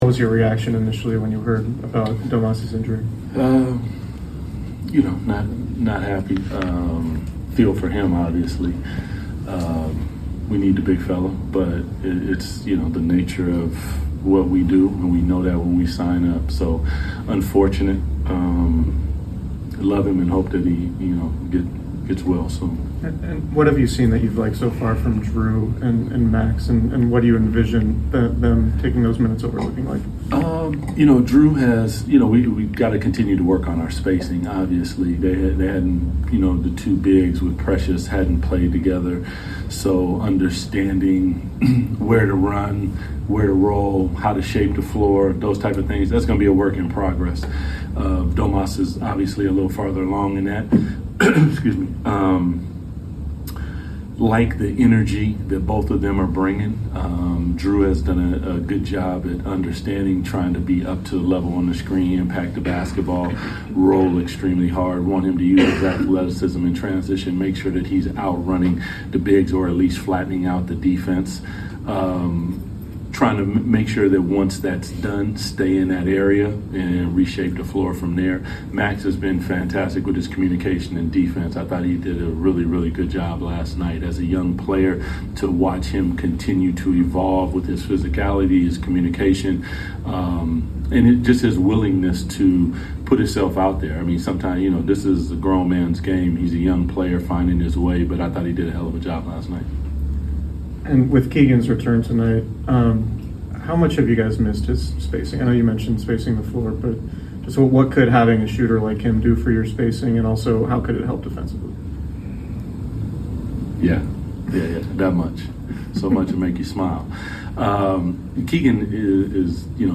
Sacramento Kings Coach Doug Christie Pregame Interview before taking on the Memphis Grizzlies at FedExForum.